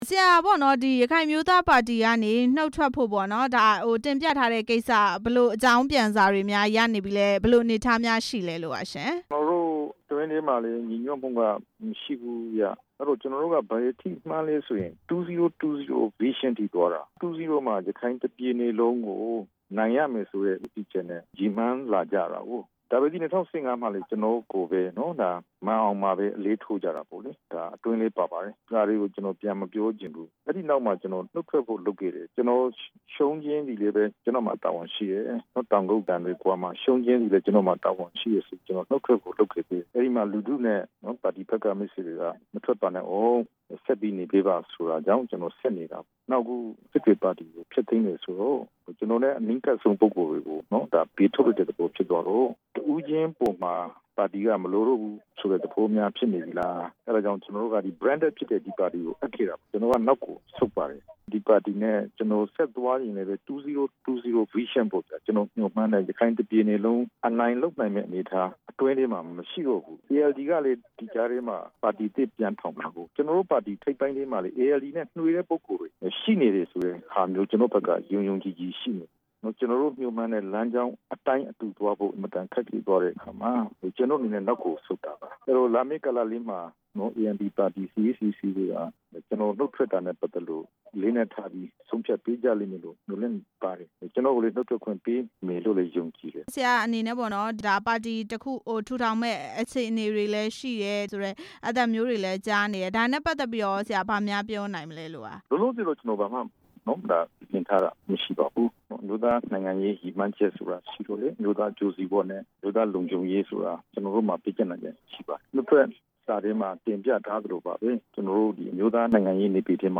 ဒေါက်တာအေးမောင်နဲ့ ဆက်သွယ်မေးမြန်းချက်